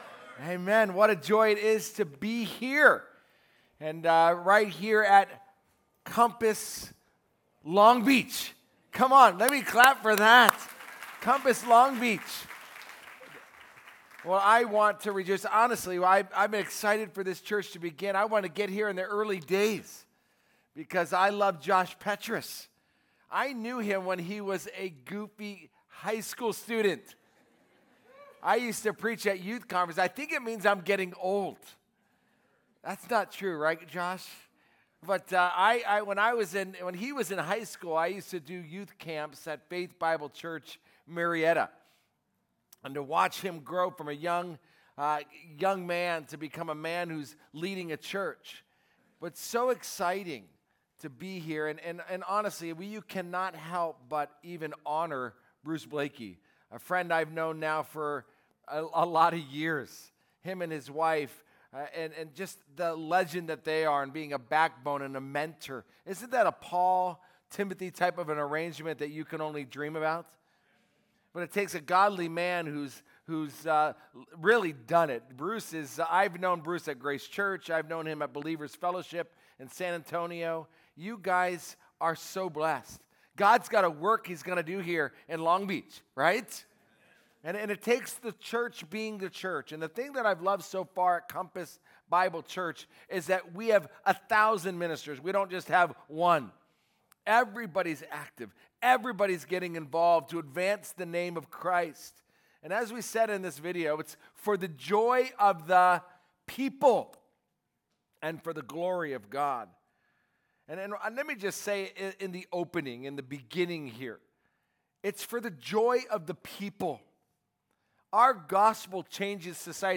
Shine - You are Salt and Light (Sermon) - Compass Bible Church Long Beach